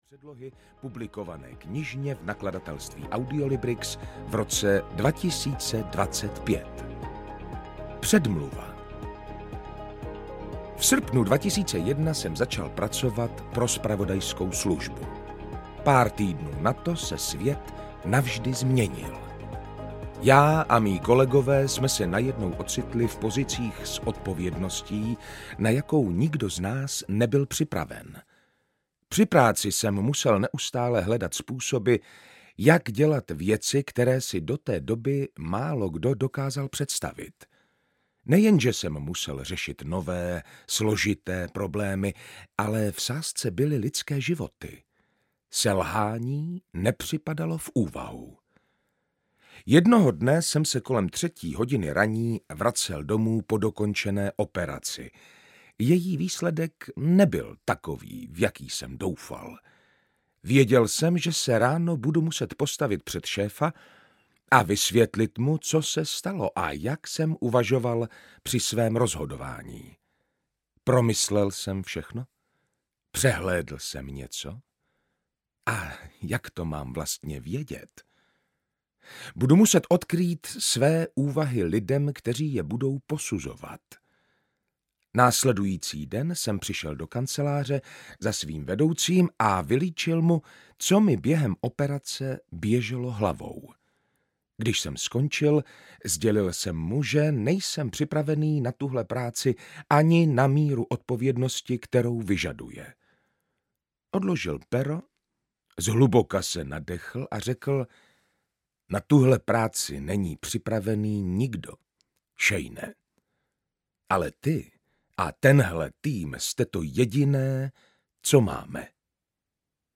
Jasné myšlení audiokniha
Ukázka z knihy